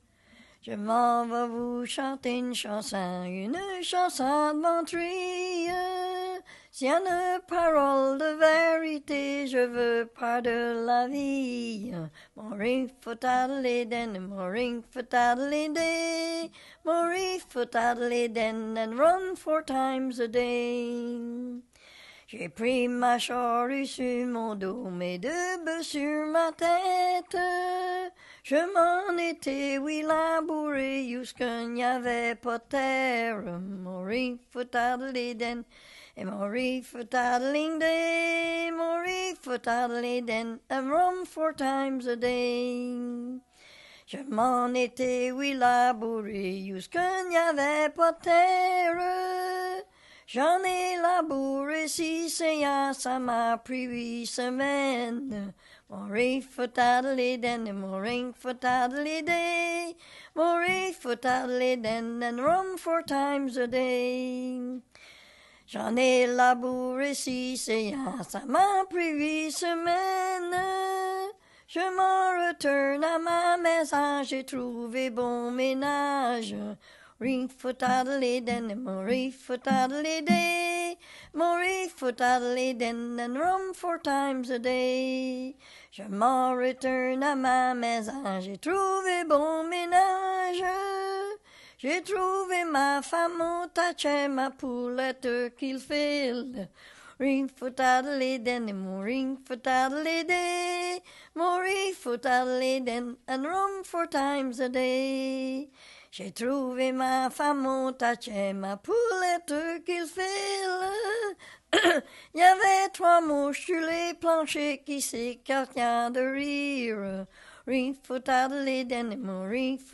Chanson Item Type Metadata
Emplacement Upper Ferry